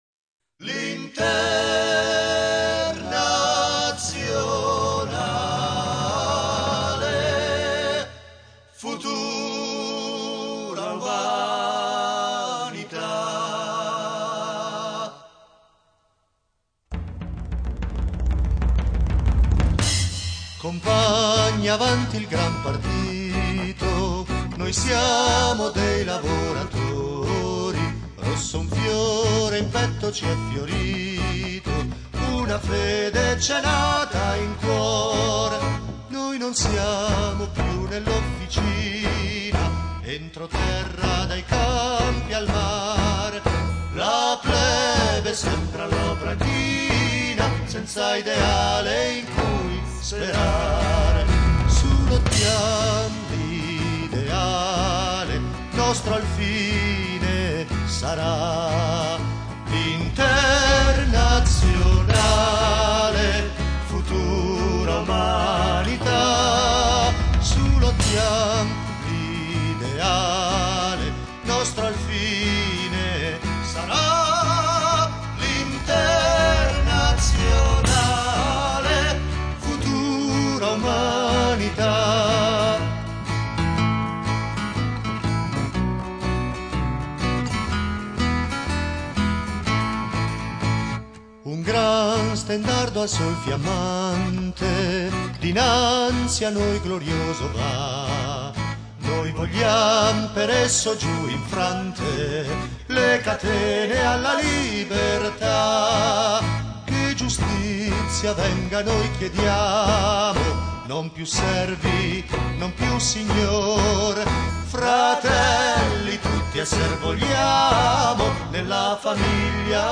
Описание: Очень мелодичная итальянская версия